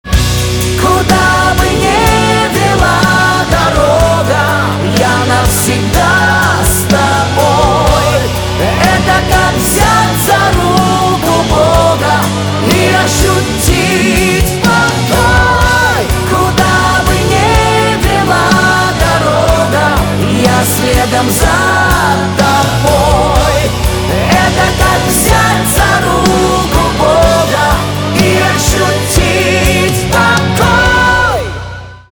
поп
чувственные
гитара , барабаны